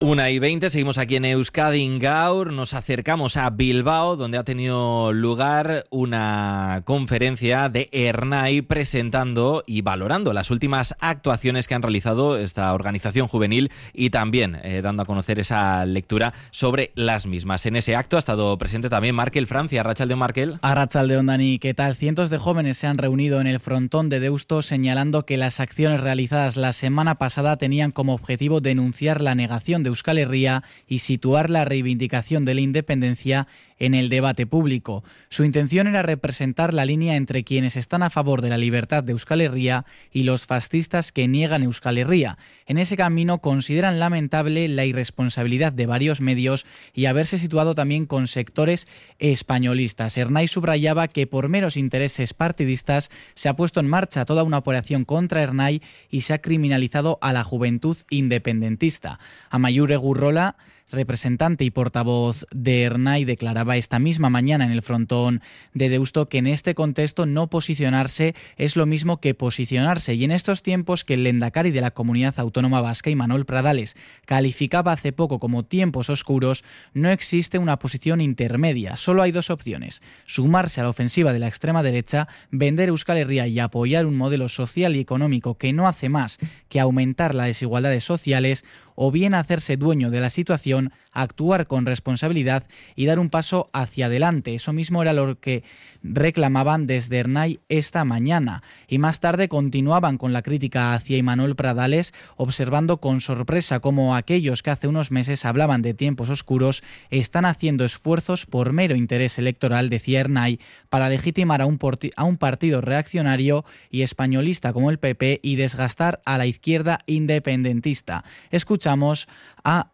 CRONICA-ERNAI.mp3